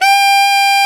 SAX A.FF G0L.wav